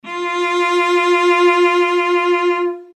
cello